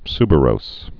(sbə-rōs) also su·ber·ous (-bər-əs)